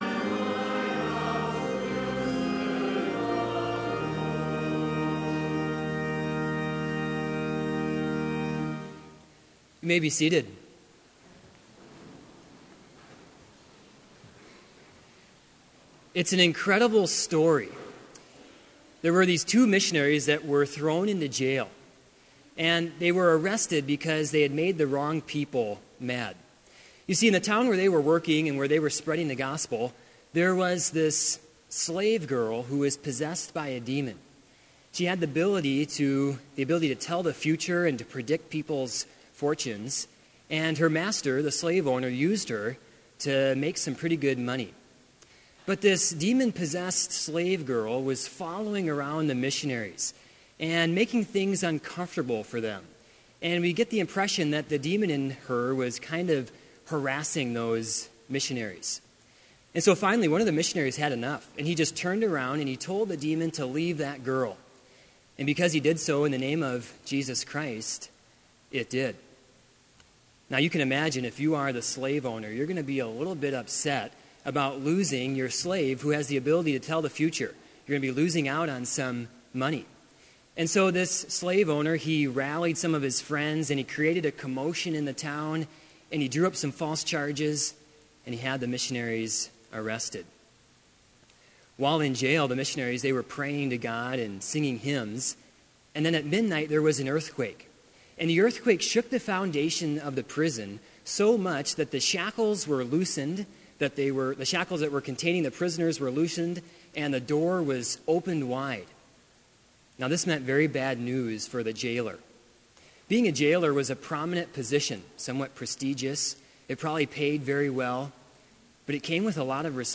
Sermon audio for Chapel - January 20, 2016